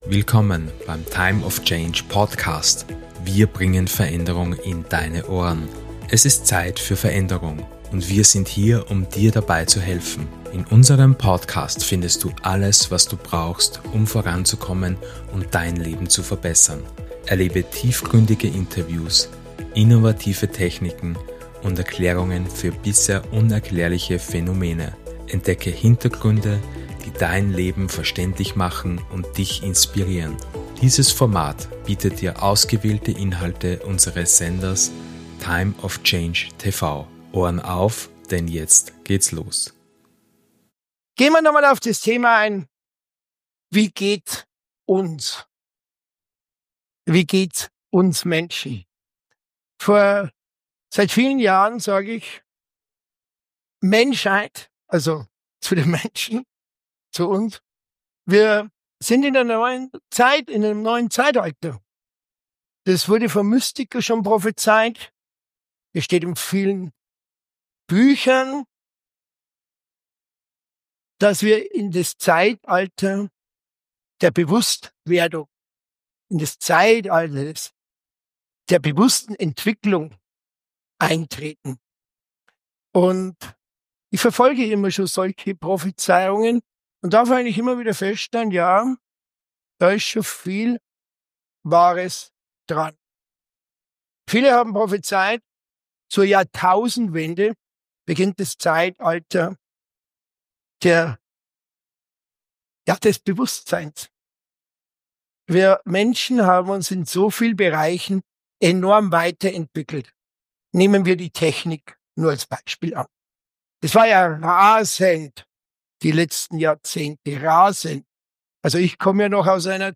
Willkommen zu Tag 1, der ersten Stunde unserer inspirierenden Days of Change Seminarreihe zum Thema 'Der Gefühlsalchemist'!